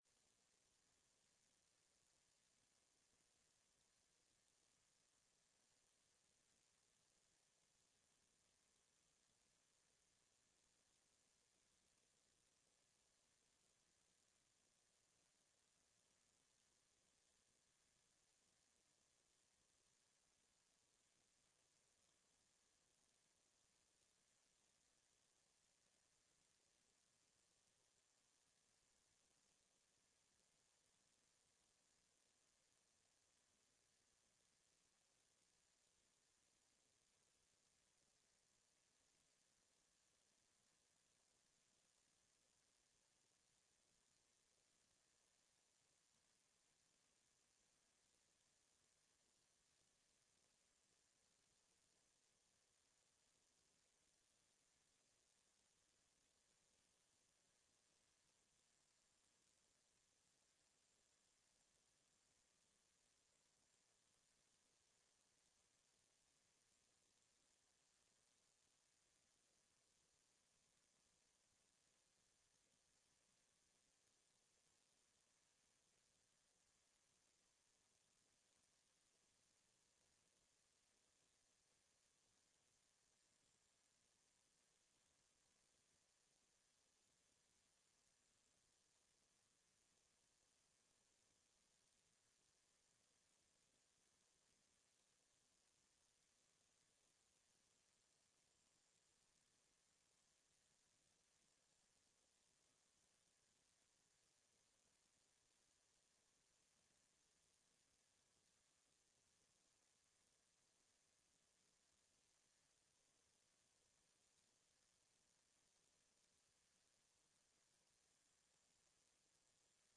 Sessão plenária do dia 19-10-15